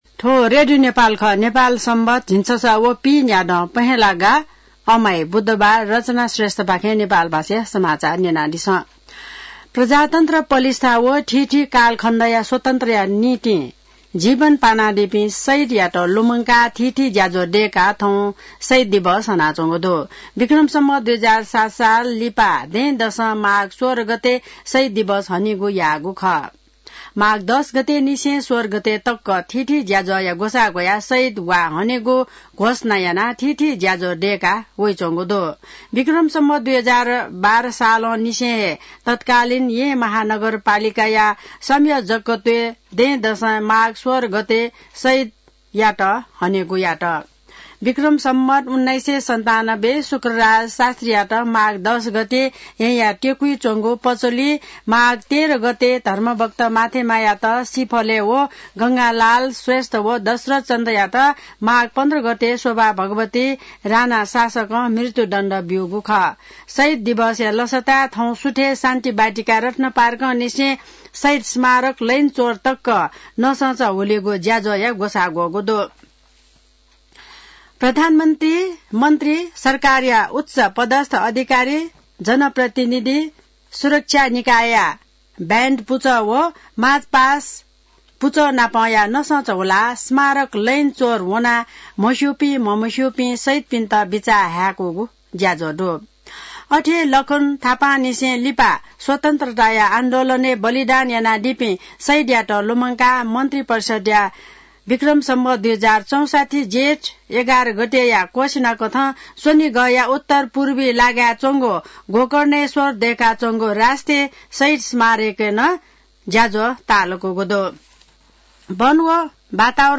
नेपाल भाषामा समाचार : १७ माघ , २०८१